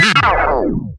Record Scratch
An abrupt vinyl record scratch stopping the music for a comedic freeze moment
record-scratch.mp3